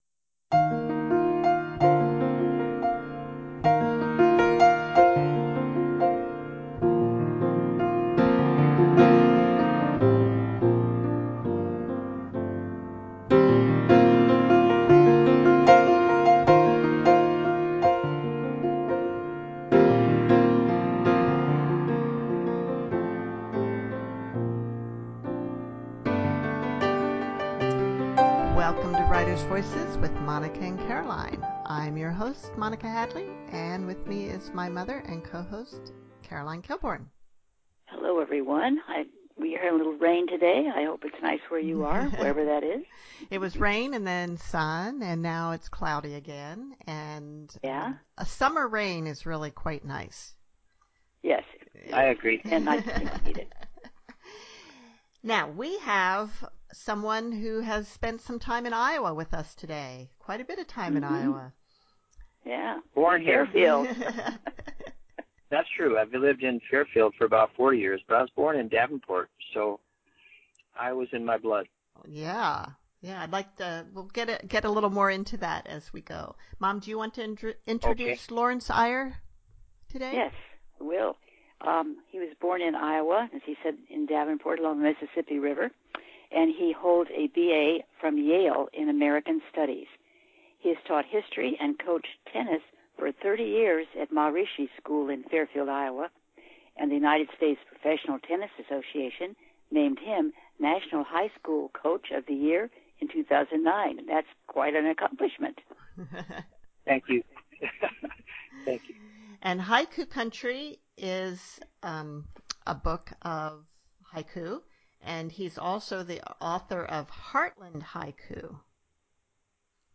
His first two collections are “Heartland Haiku” and “”Haiku Country,” both of which we explore in this delightful conversation.